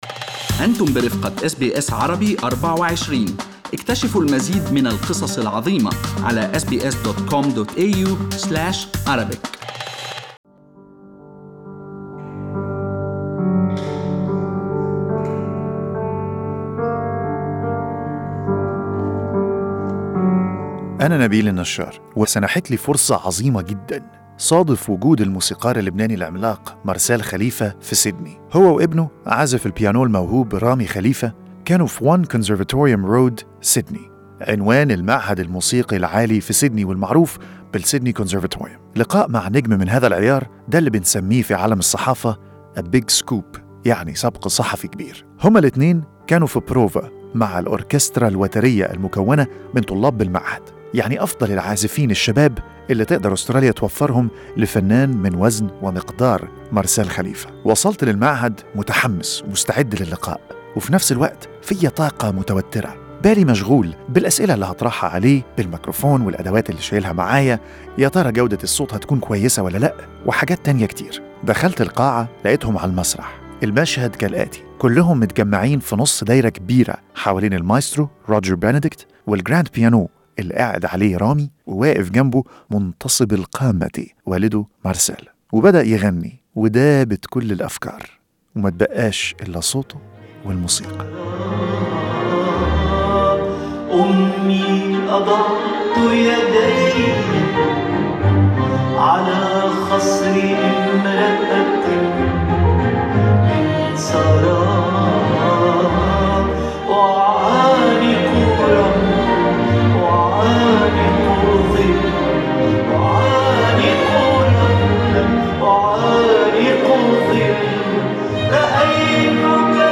في هذا اللقاء الخاص، سمح لنا الموسيقار العملاق مارسيل خليفة بسرقة نظرة خاطفة من وراء الكواليس، لنرى كيف يحضر المايسترو الكبير لحفلاته في جلسة تدريب خاصة.
Marcel Khalife at the Sydney Conservatorium during rehearsals on Wednesday 28/10/2020 Source: Arabic Theatre Studio